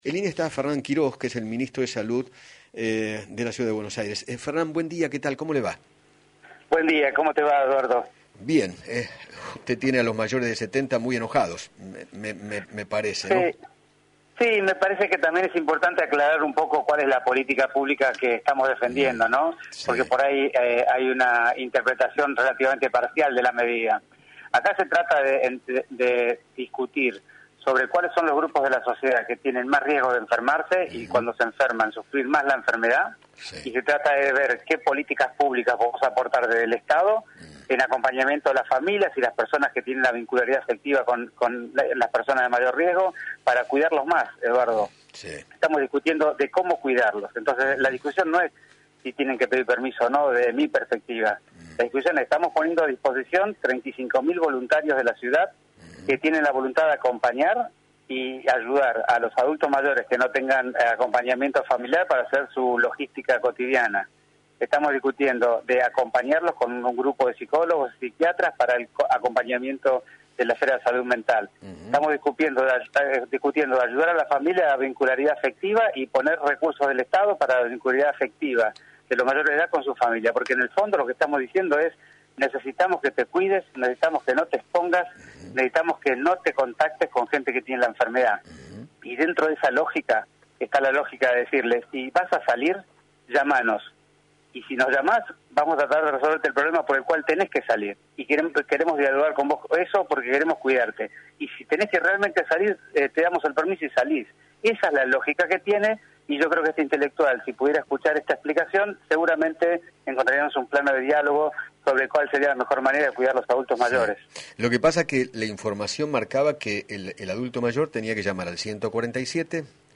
Fernán Quiros, Ministro de salud Porteño, dialogó con Eduardo Feinmann sobre la decisión del Gobierno Porteño de implementar un permiso especial para que las personas mayores de 70 años salgan a la calle.